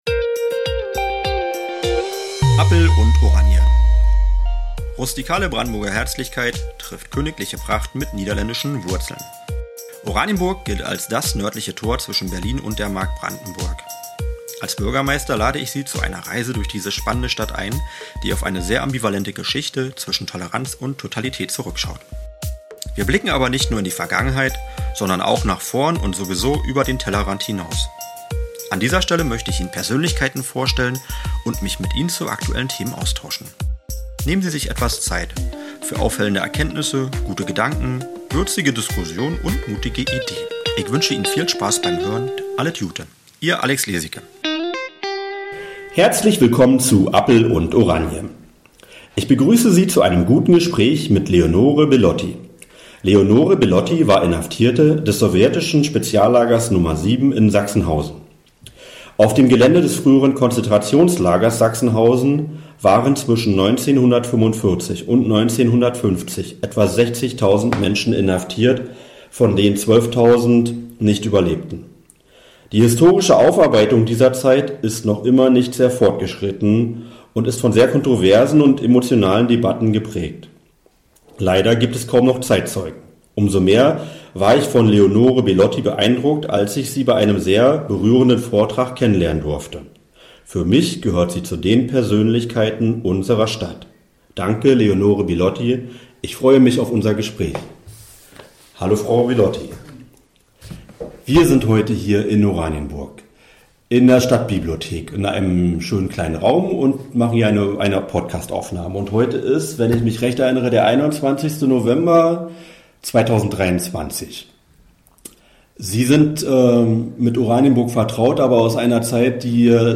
Überlebende des sowjetischen Straflagers Nummer 7 in Sachsenhausen ~ Appel und Oranje Podcast